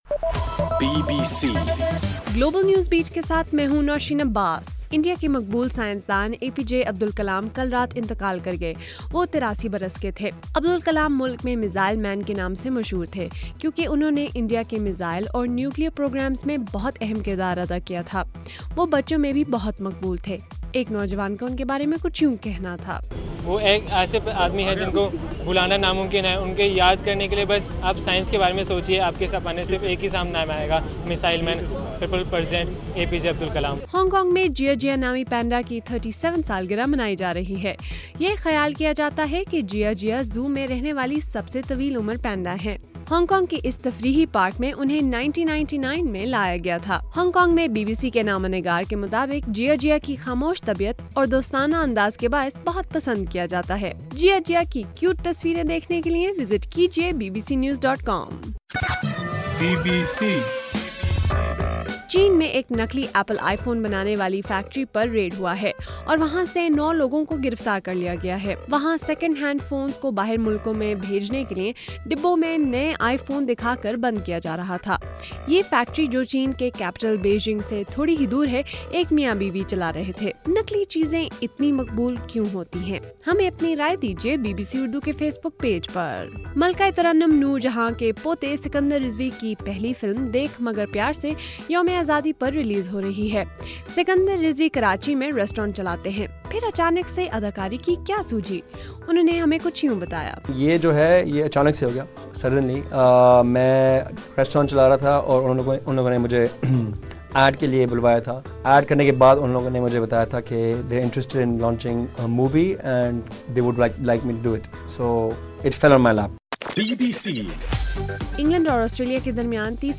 جولائی29: صبح1 بجے کا گلوبل نیوز بیٹ بُلیٹن